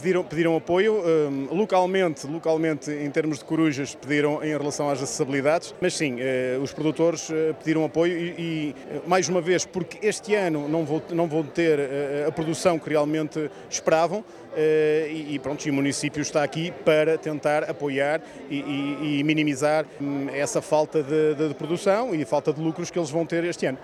Na sua primeira inauguração oficial enquanto presidente da Câmara Municipal de Macedo de Cavaleiros, Sérgio Borges revelou ter recebido diversos pedidos de apoio dos agricultores, face à quebra de produção registada este ano: